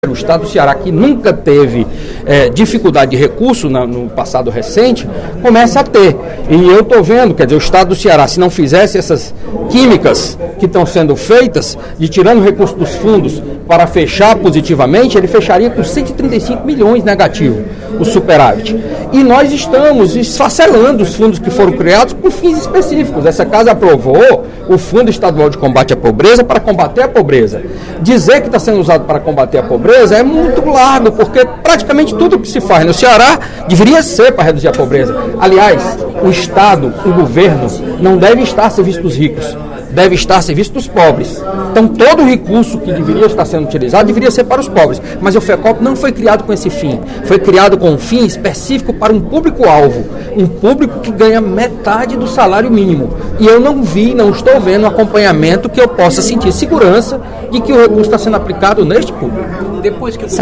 Durante o primeiro expediente da sessão plenária desta terça-feira (07/04), o deputado Carlos Matos (PSDB) criticou a maneira como o Fundo de Combate à Pobreza (Fecop) é utilizado no Estado. Na avaliação dele, os recursos para o combate à pobreza deveriam ter um enfoque mais abrangente, e não serem pulverizados em diferentes órgãos do Governo e utilizados apenas para atender programas das secretarias.